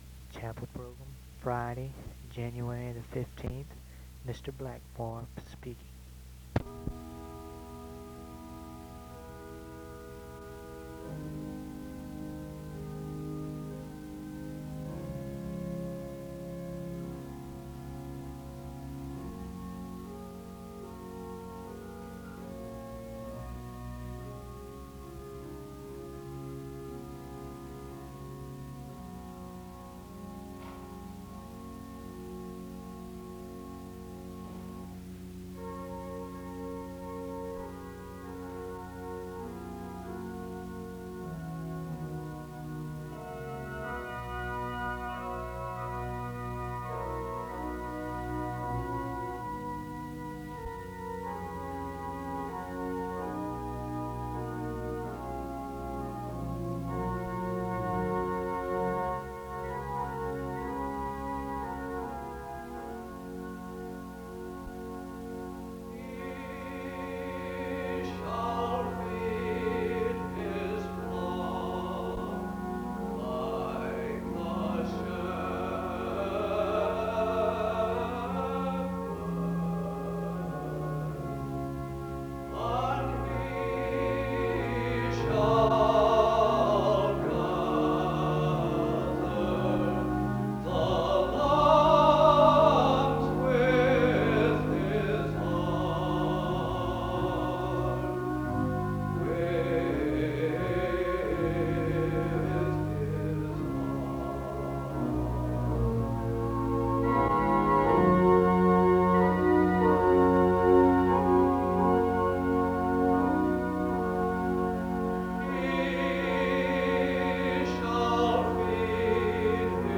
The service begins with music from 0:00-3:43. A prayer is offered from 3:45-4:48.
A responsive reading is done from 8:54-9:40. Various verses from Isaiah 40, Luke 15, and John 10 are read from 9:45-12:32. Music plays from 12:33-12:50.